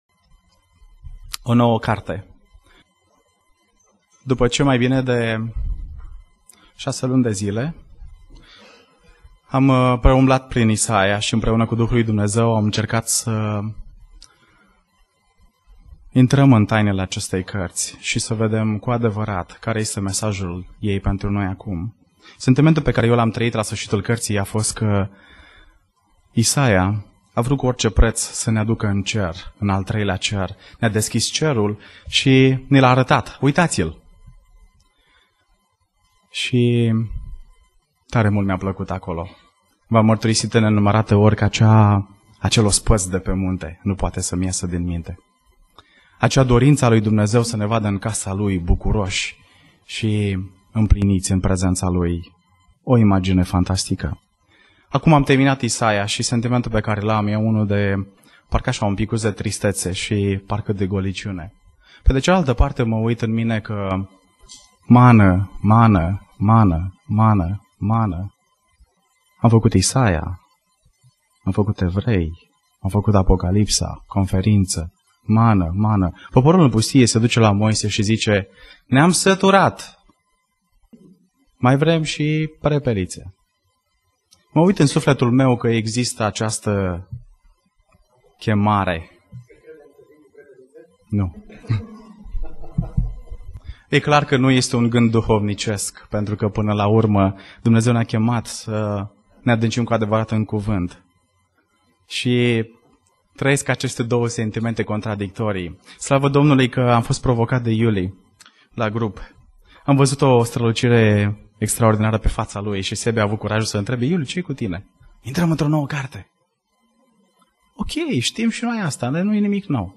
Predica Exegeza - 1 Tesaloniceni Introducere